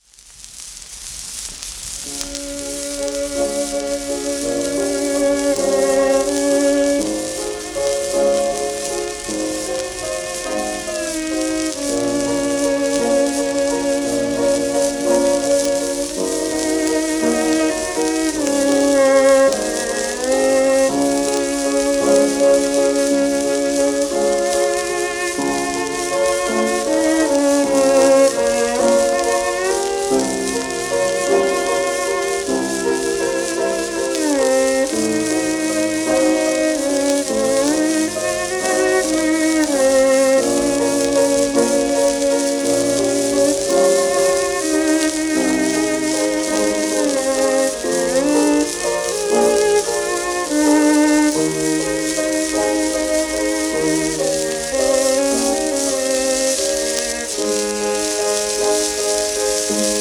w/ピアノ
1925年頃録音